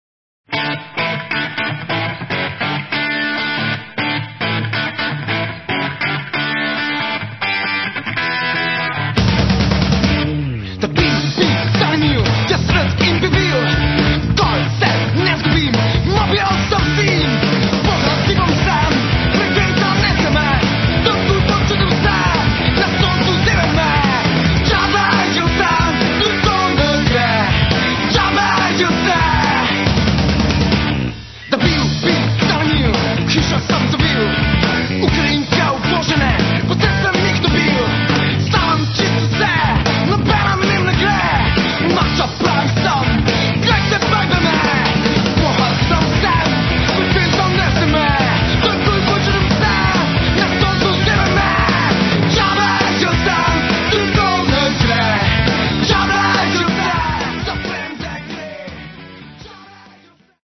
spremljevalni vokali
saksofonom